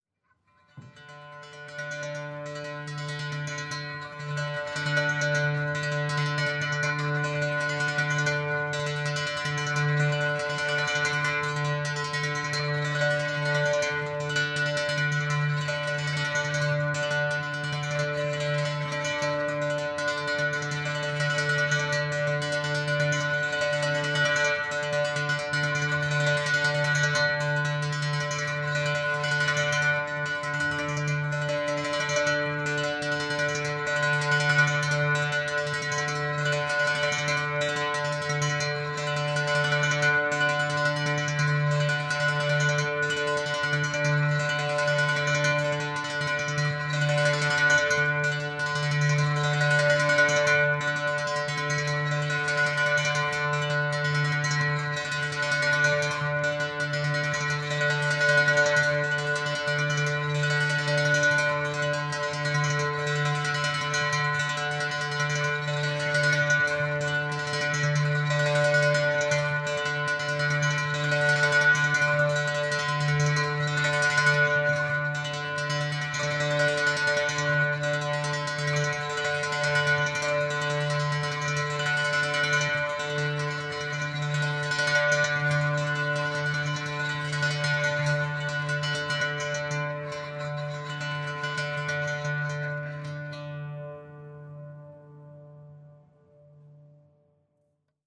• Unison Monochord therapy D - D - 129.mp3
unison_monochord_therapy_d_-_d_-_129_g1n.wav